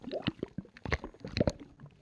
喝水3zth075018.wav
通用动作/01人物/02普通动作类/喝水3zth075018.wav
• 声道 單聲道 (1ch)